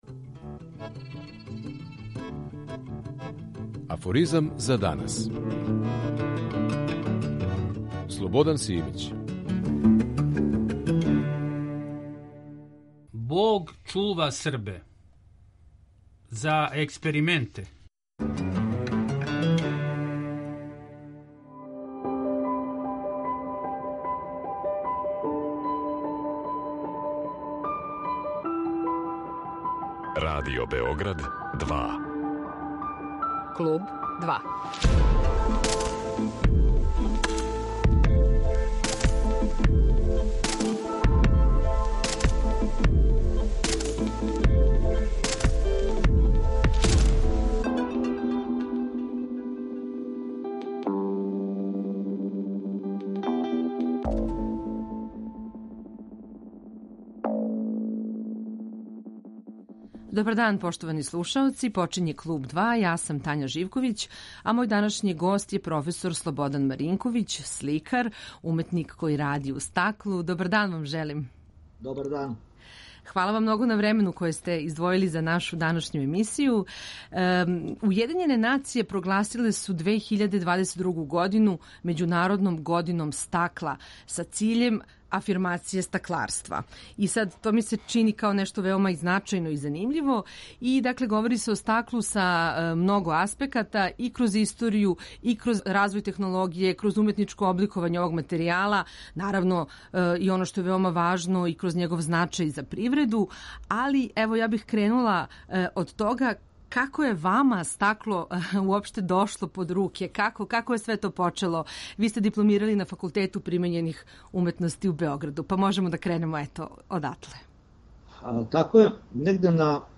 Аудио подкаст Радио Београд 2